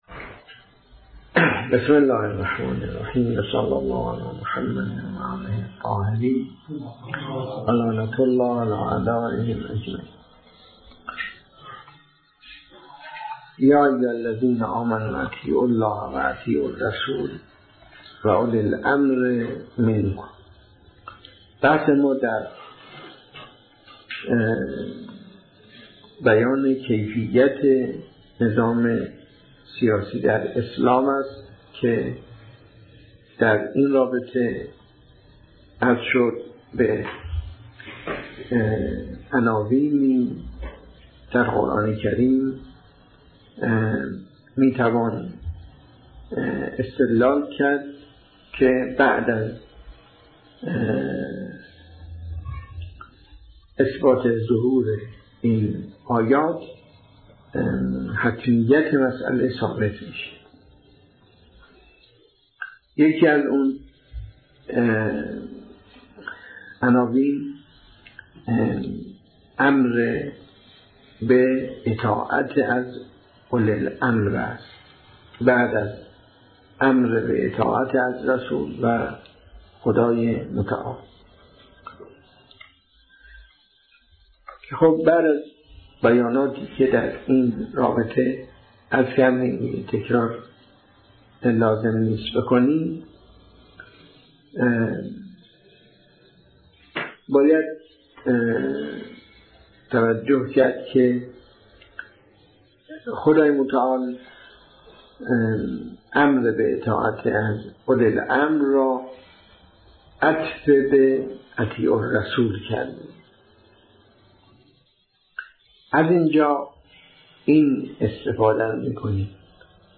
درس خارج فقه السیاسه جلسه هجدهم